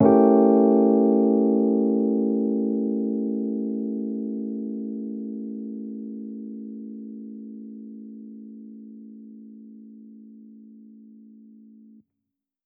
Index of /musicradar/jazz-keys-samples/Chord Hits/Electric Piano 2
JK_ElPiano2_Chord-A7b9.wav